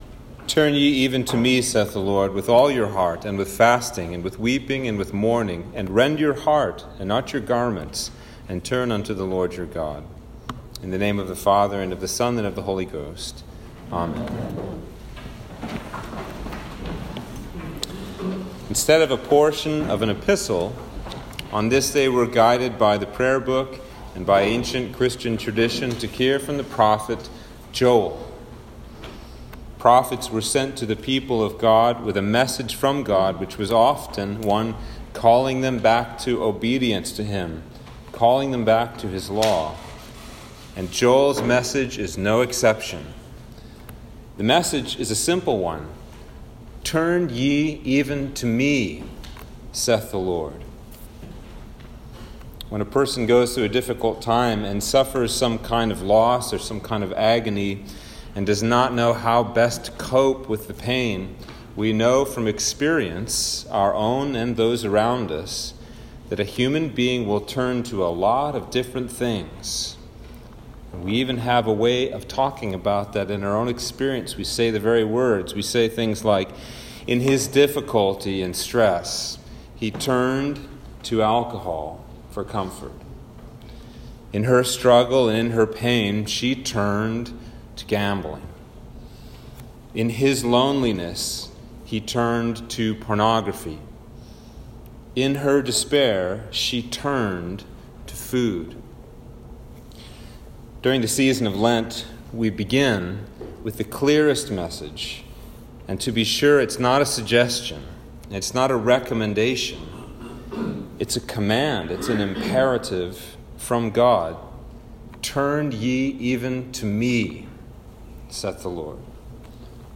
Sermon for Ash Wednesday - 2022